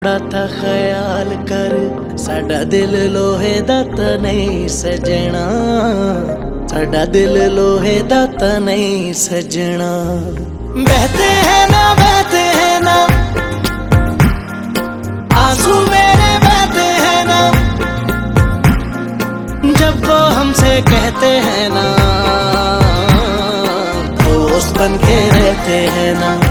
uplifting melodies
joyful rhythm and soul-stirring vocals